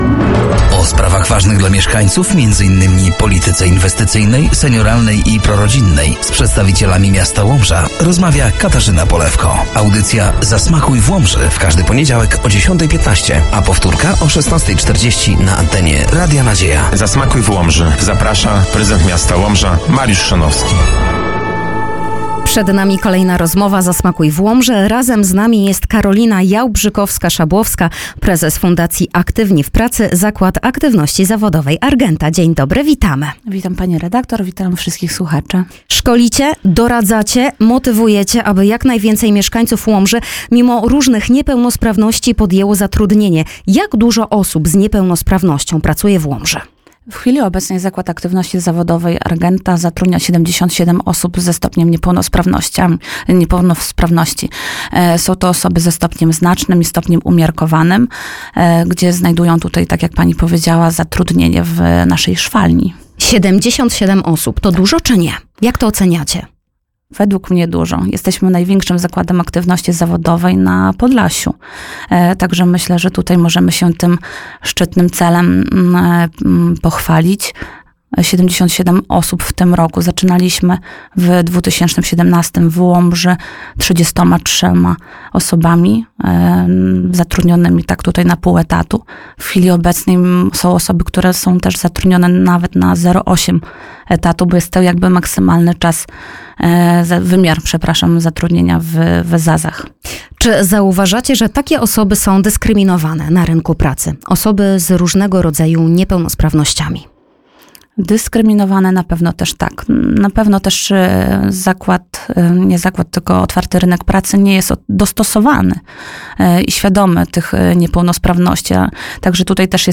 Audycja „Zasmakuj w Łomży”, w każdy poniedziałek o 10.15 na antenie Radia Nadzieja.